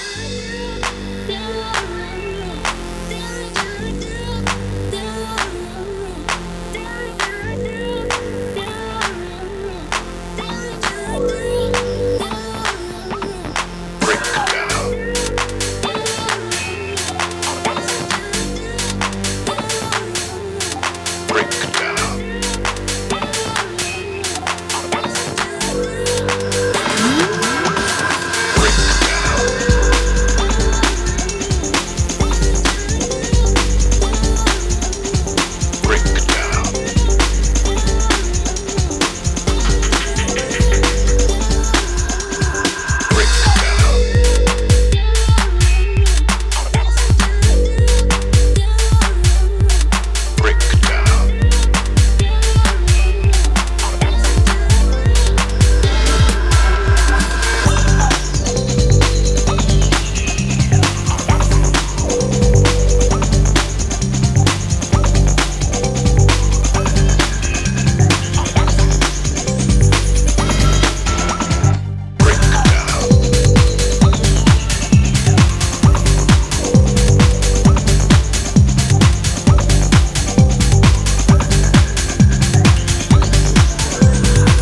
a front flip into curbside clubbing.
the beefed-out break tune
the straight and narrow slammings